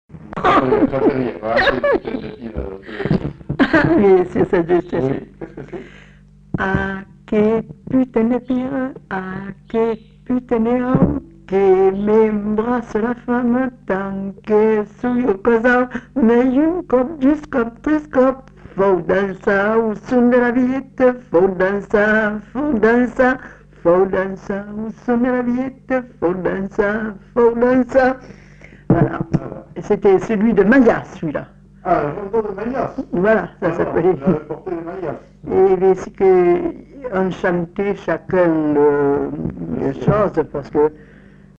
Aire culturelle : Bazadais
Lieu : Grignols
Genre : chant
Type de voix : voix de femme
Production du son : chanté
Danse : bigue-biguette